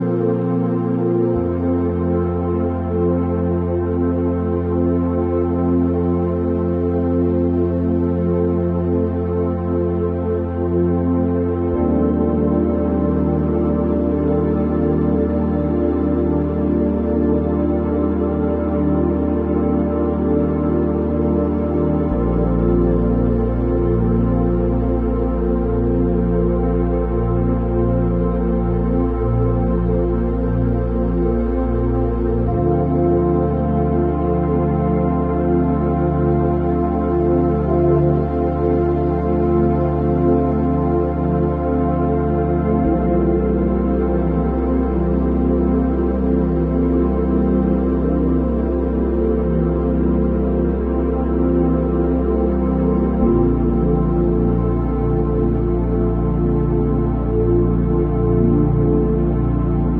Feel grounded and at peace with 174 Hz – the frequency of pain relief and deep healing. Let the vibrations soothe your soul.